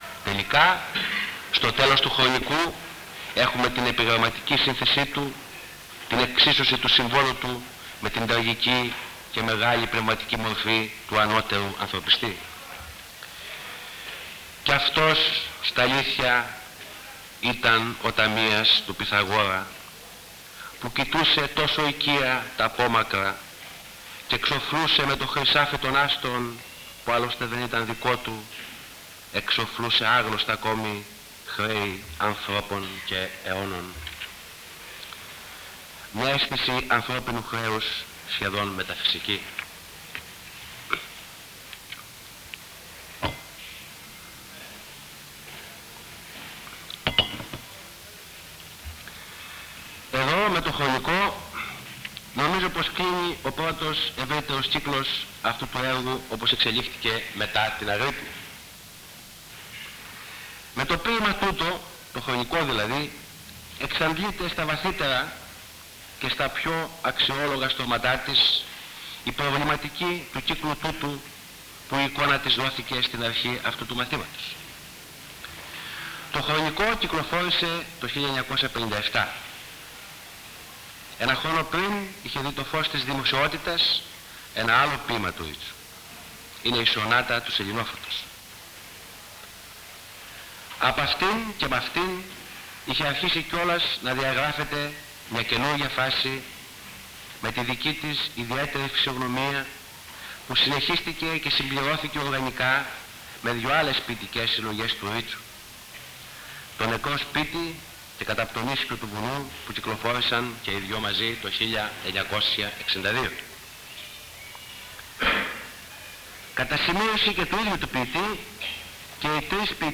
Εξειδίκευση τύπου : Εκδήλωση
Περιγραφή: Κύκλος Μαθημάτων με γενικό Θέμα "Σύγχρονοι Νεοέλληνες Ποιητές"